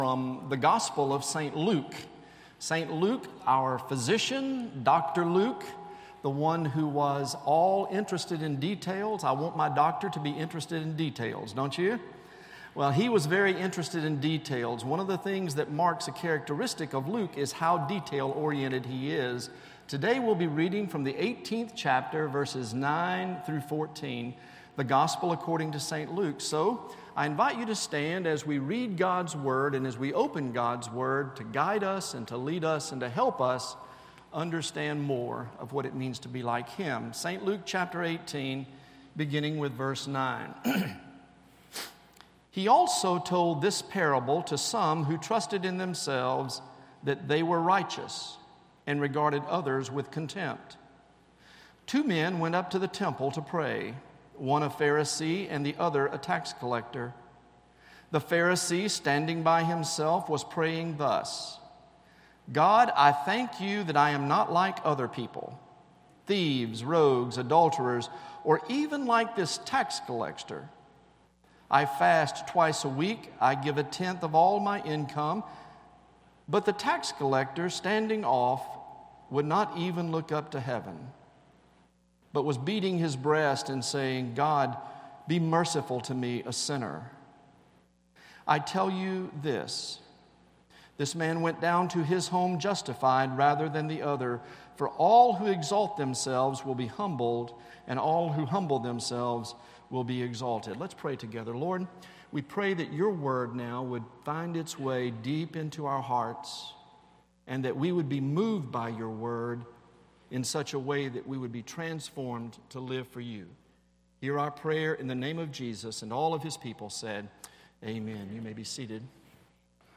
Sermon Archives - Cokesbury Church